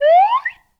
whistle_slide_up_05.wav